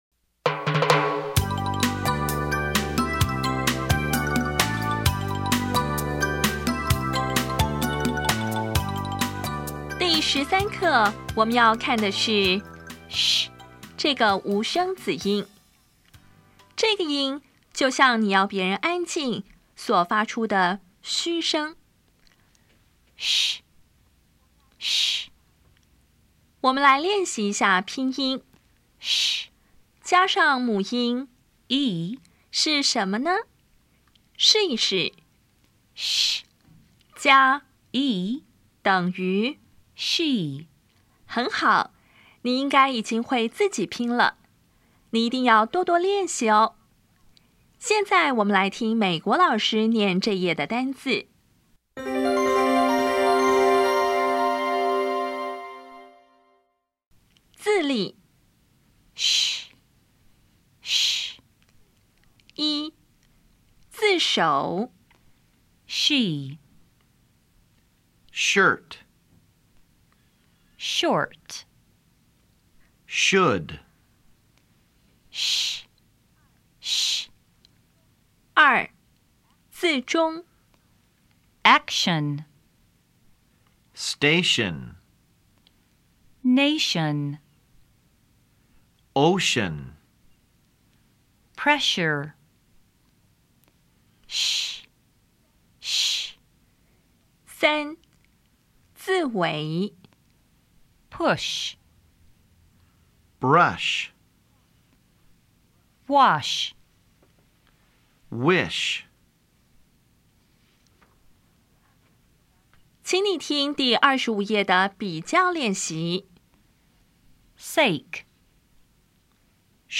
当前位置：Home 英语教材 KK 音标发音 子音部分-1: 无声子音 [ʃ]
音标讲解第十三课
比较 [s] [ʃ]      [s](无声) [ʃ](无声)